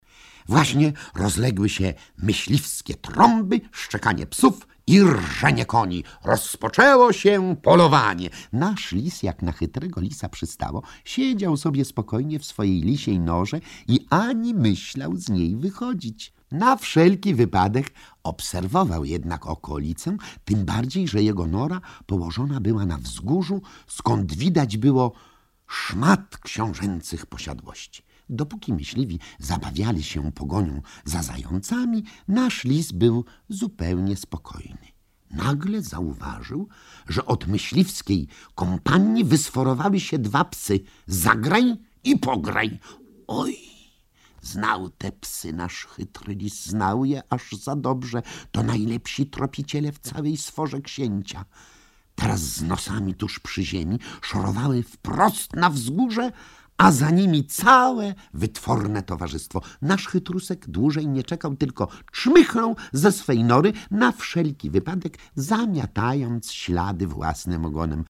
O chytrym lisie, psach Zagraju i Pograju i kurniku księcia pana opowiada Mieczysław Gajda, niezapomniany smerf Maruda.
Akcja zaczyna się od myśliwskich trąb, szczekania psów i rżenia koni.